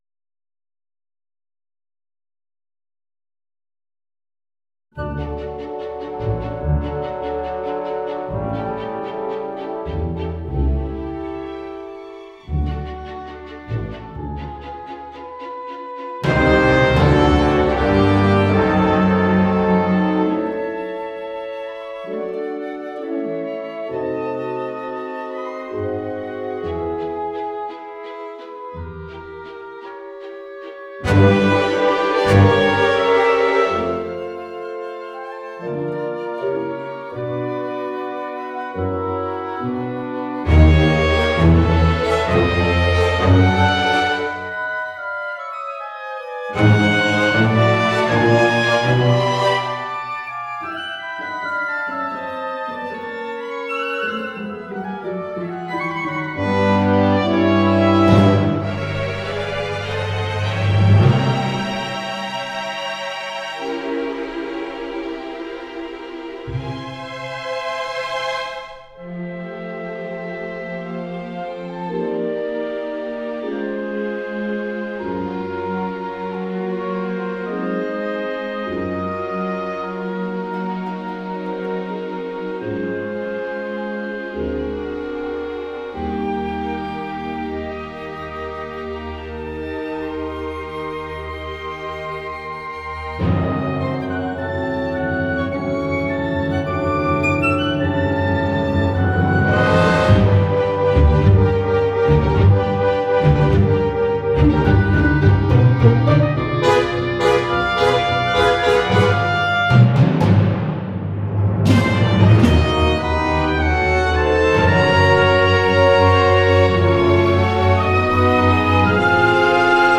The audio I’m sharing is already sped up, so you don’t have to do any of this, but here’s how this works.
(There’s about 5 seconds of silence at the beginning for Jinxy’s title card.)